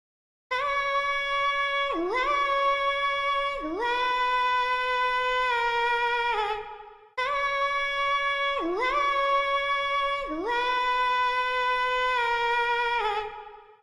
glowl sounds like a softer sound effects free download
glowl sounds like a softer yelmut